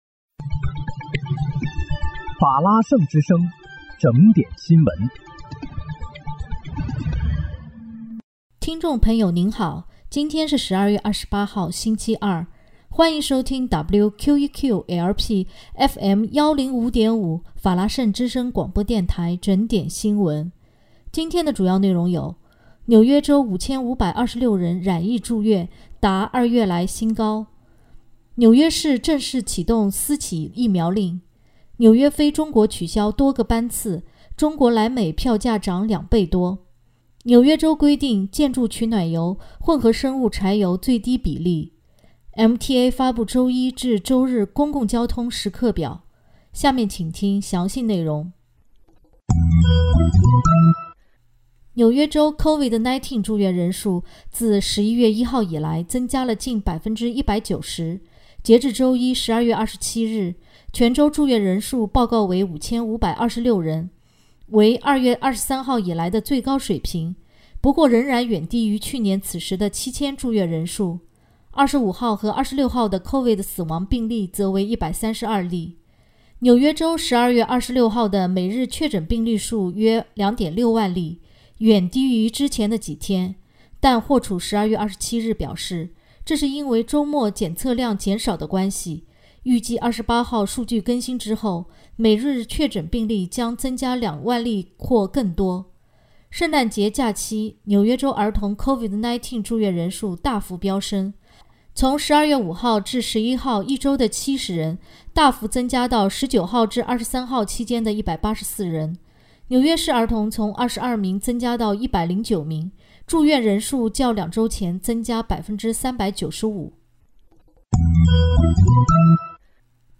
12月28日（星期二）纽约整点新闻